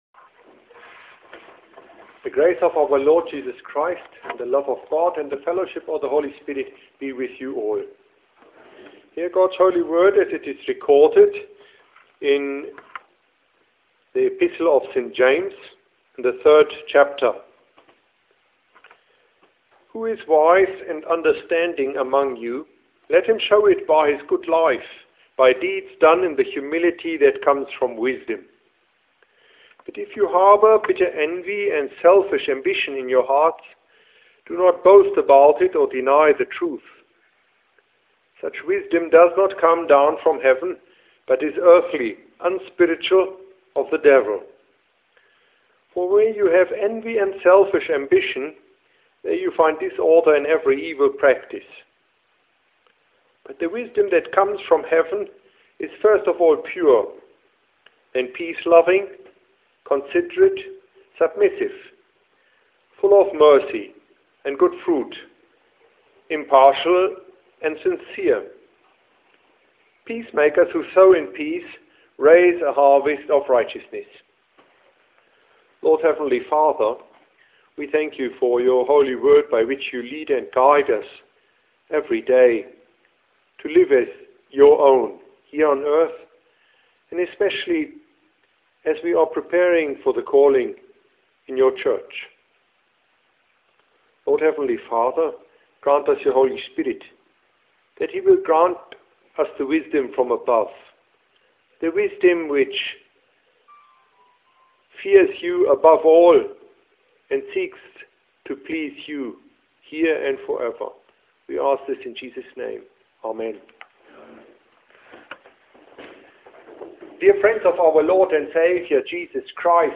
Confessional Address at LTS
Wednesdays we have Confession and Absolution at the Seminary.